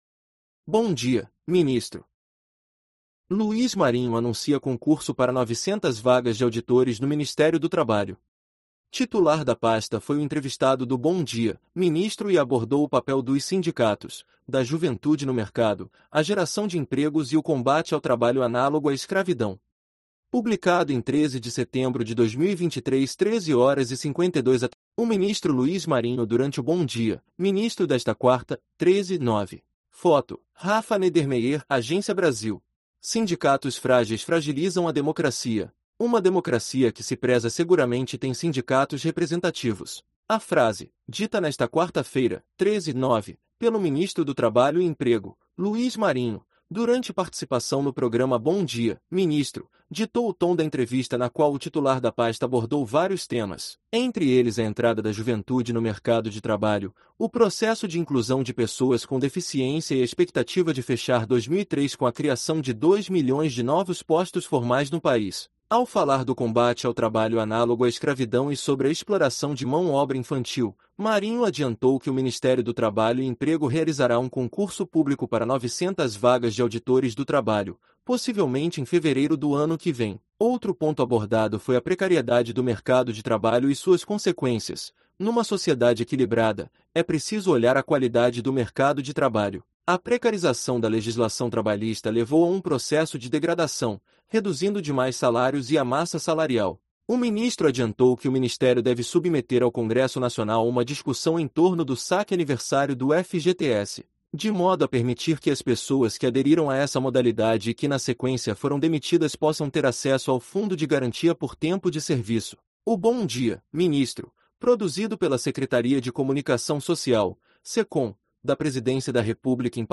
Titular da pasta foi o entrevistado do Bom Dia, Ministro e abordou o papel dos sindicatos, da juventude no mercado, a geração de empregos e o combate ao trabalho análogo à escravidão